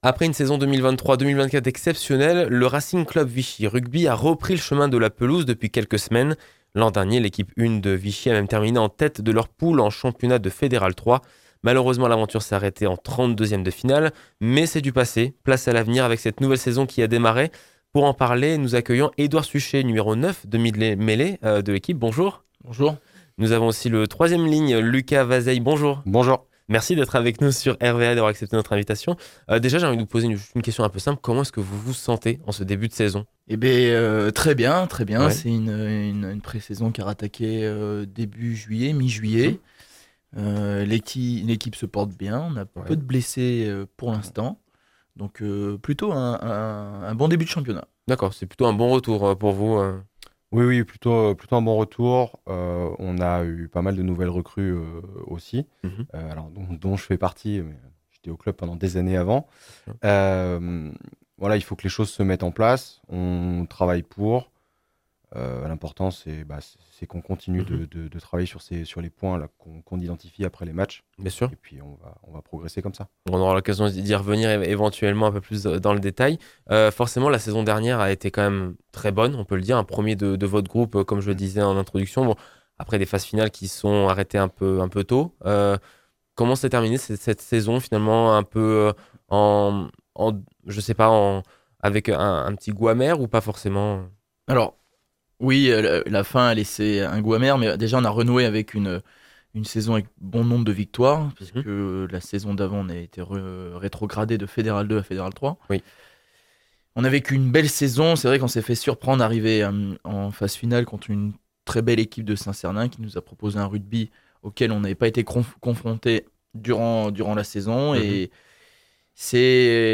Cette semaine, RVA reçoit deux joueurs du Racing Club Vichy Rugby (Allier) :